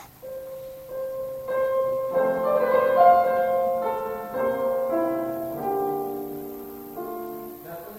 要象拥抱一样，非常有感情，带着爱去弹。
现在换一种：